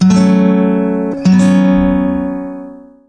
CHORDS
1 channel